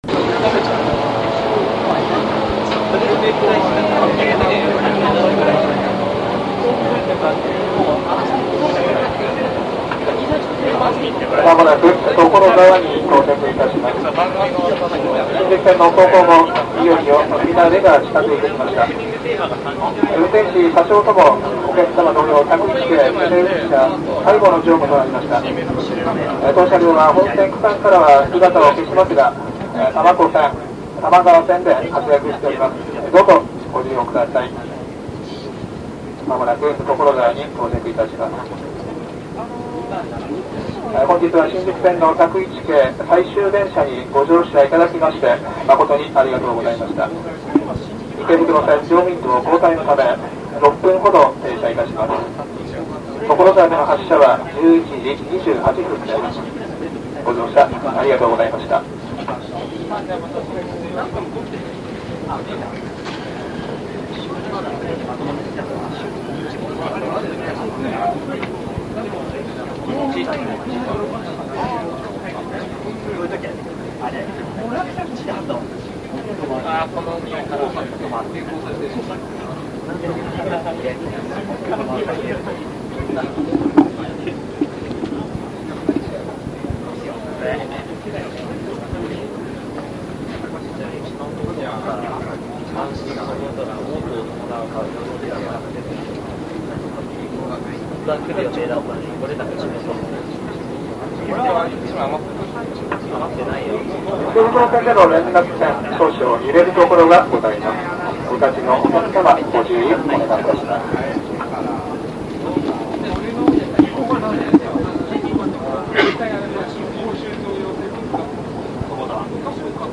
〜秩父   西武旧101 廃車回送を兼ねた定員制イベント列車「さよなら101系」の道中。
193F+197F8連の内、モハ194のほぼAK-3直上に乗車。